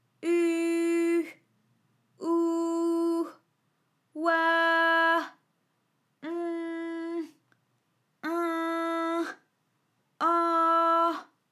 ALYS-DB-001-FRA - First, previously private, UTAU French vocal library of ALYS
-uhh-ouhh-oihh-onhh-inhh-anhh.wav